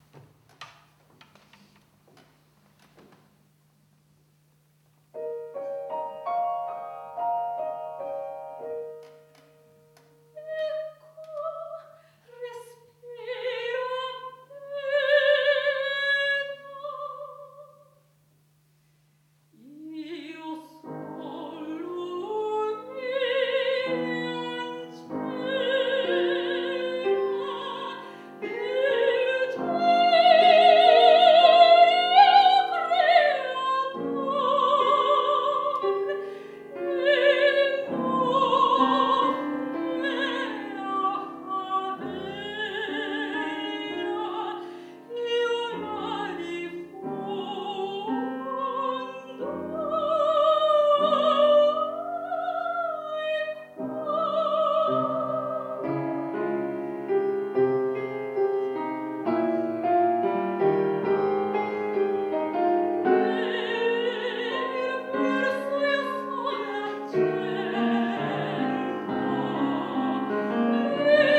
III OTTOBRE MUSICALE A PALAZZO VALPERGA
soprano
pianoforte